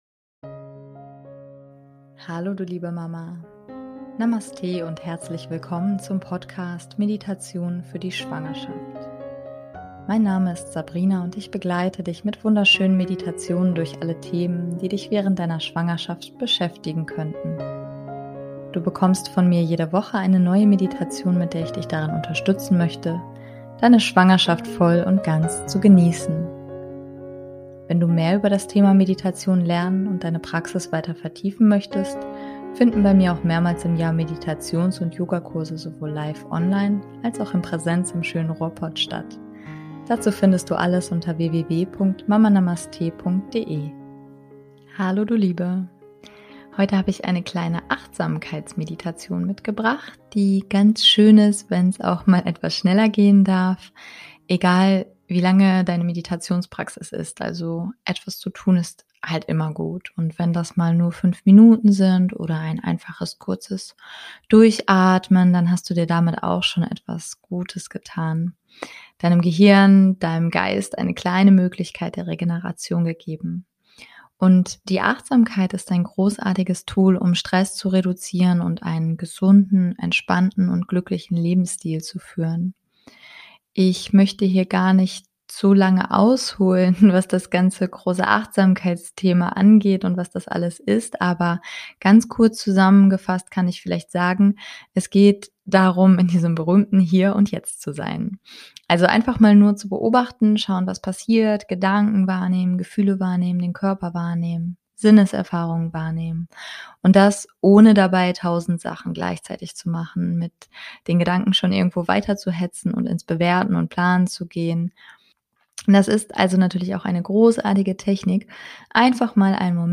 Heute habe ich eine kleine Achtsamkeitsmeditation mitgebracht.